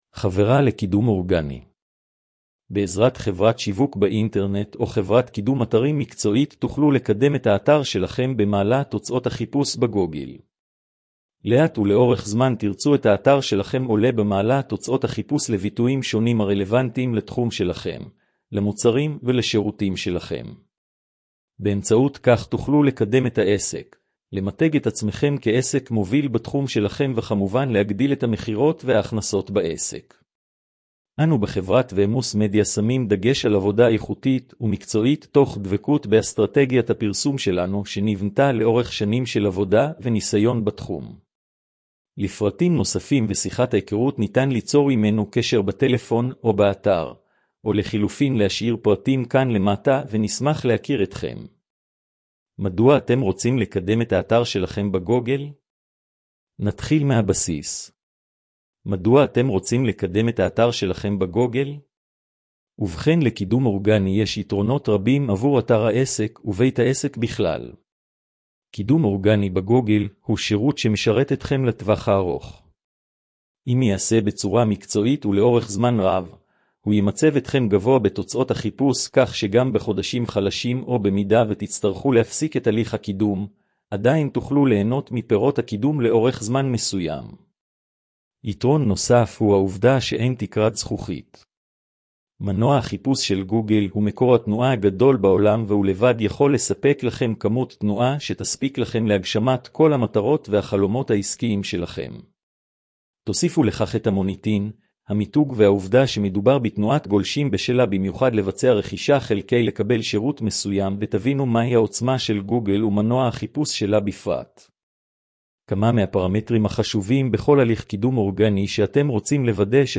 הקראת תוכן לכבדי ראייה